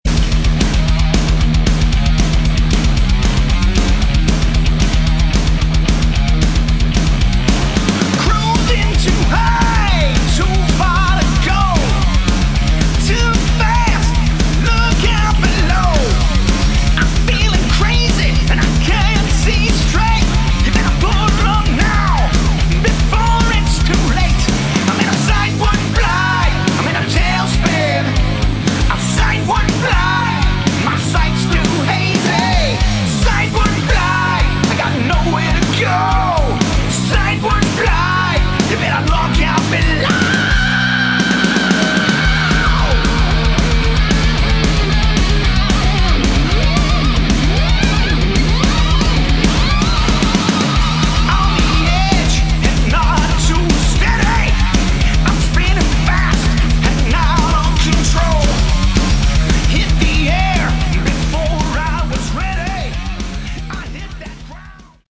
Category: Hard Rock
Lead Vocals
Lead Guitar, Vocals
Drums
Bass Guitar
Good hard rock with an excellent sound.